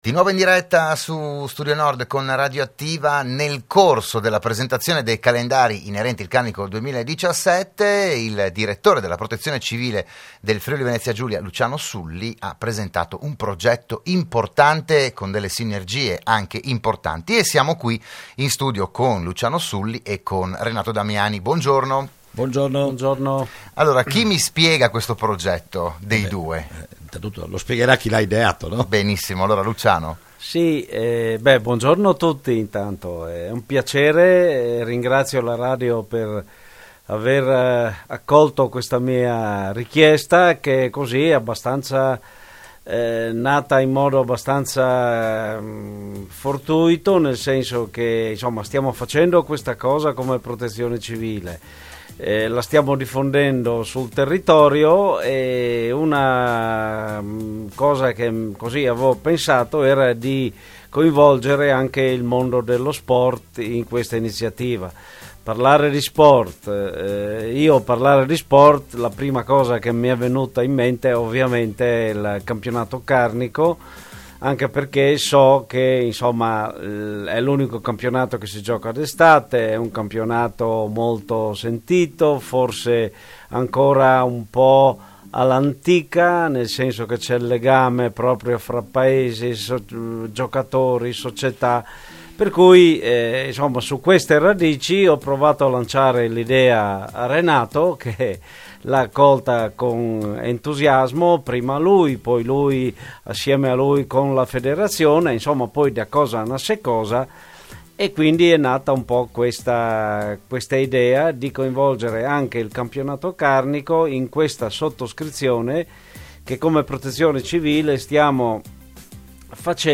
Il PODCAST dell'intervento a Radio Studio Nord